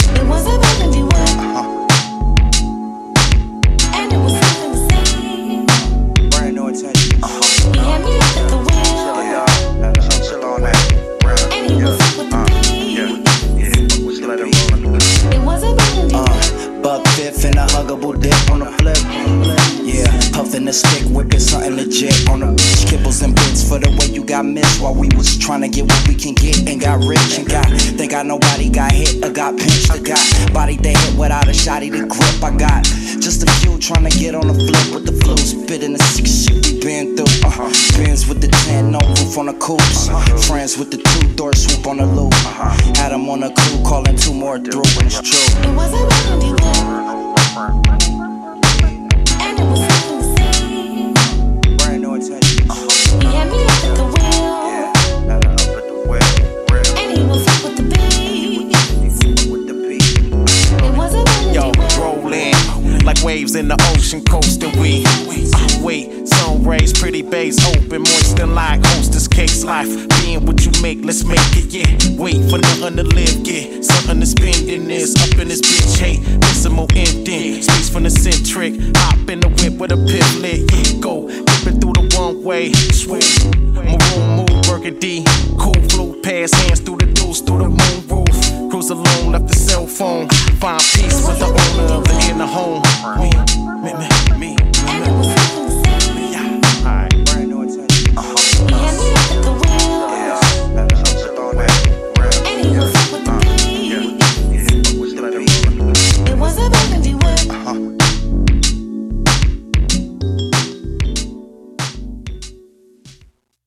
The 80s vibe is dope :ok_hand: